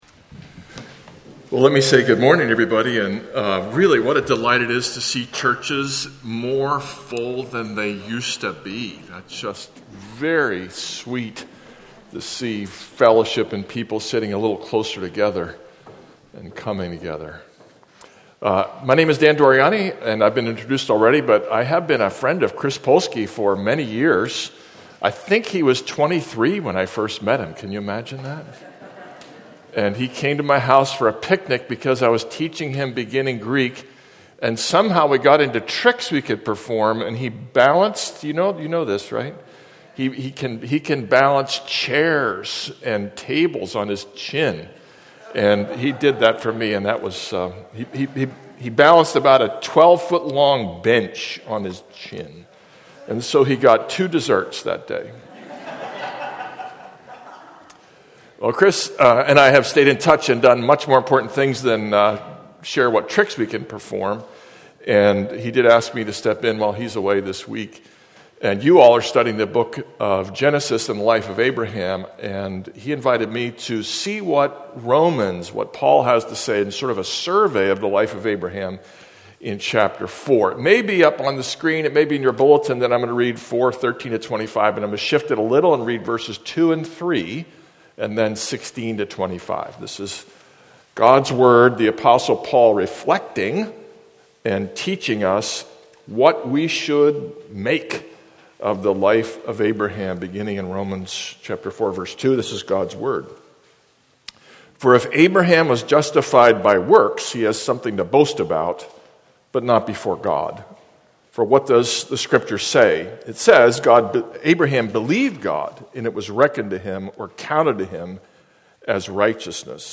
Passage: Romans 4:13-25 Service Type: Weekly Sunday Download Files Notes Bulletin « The Fear